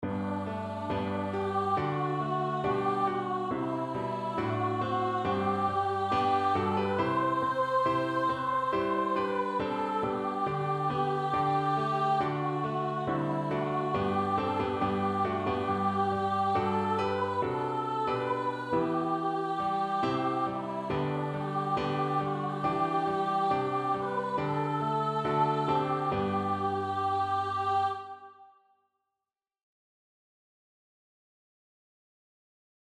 Evangeliumslieder